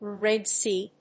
アクセントRéd Séa